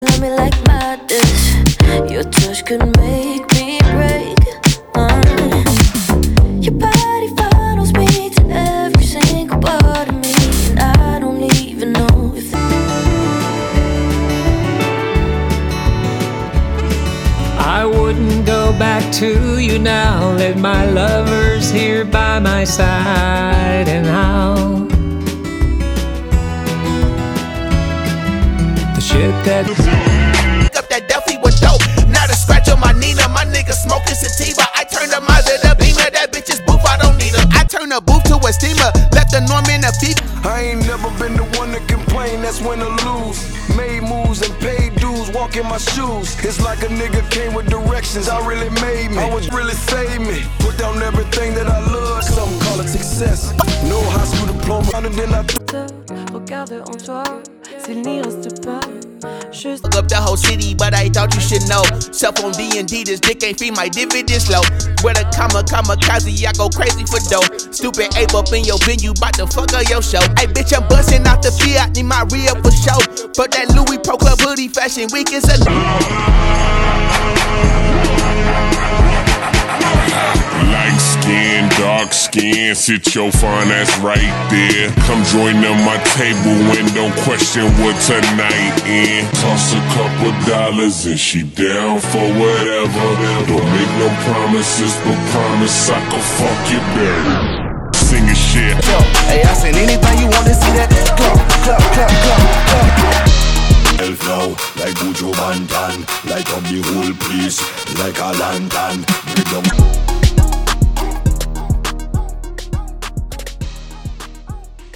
Old School Hip-Hop